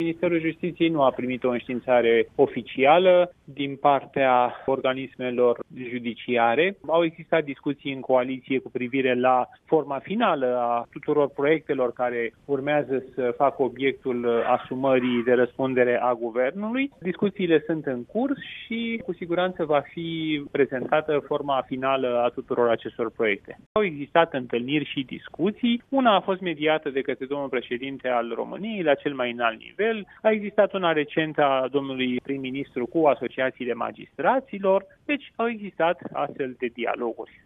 Forma finală a proiectului de lege va fi anunţată la finalizarea negocierilor, a declarat, astăzi la RRA, ministrul justiţiei, Radu Marinescu.